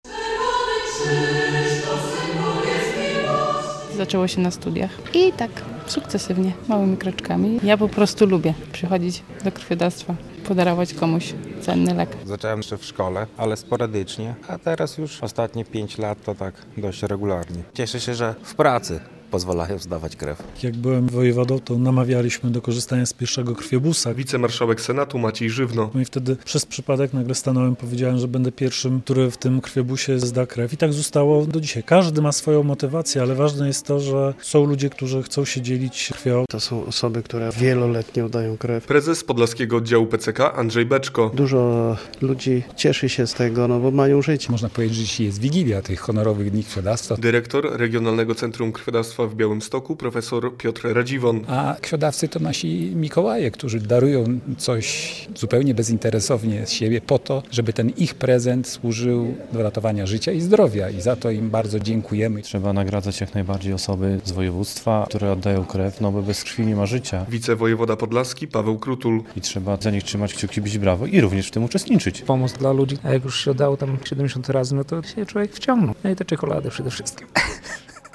Uroczystość w wigilię Dni Honorowego Krwiodawstwa - relacja